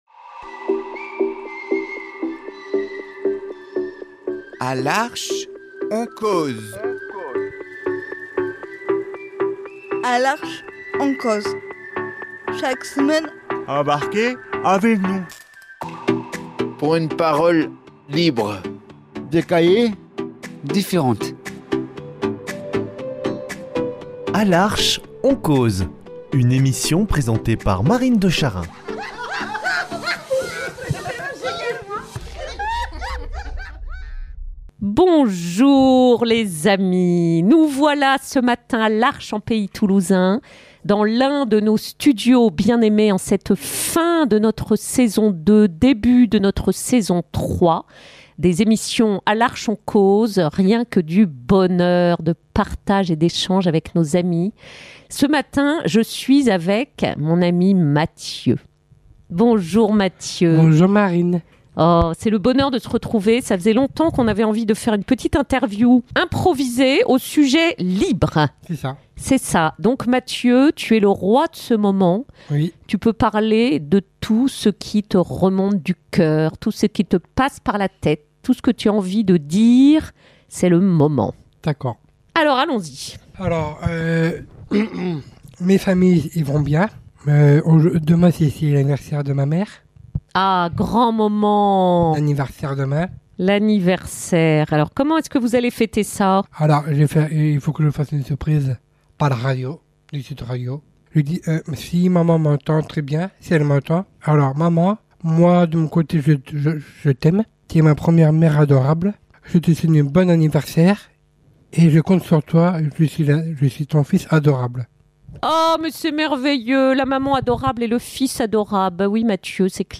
Avec sa spontanéité unique et sa passion vibrante, il clame au micro ses joies, ses liens, ses goûts, ses mercis, et nous contamine de son immense générosité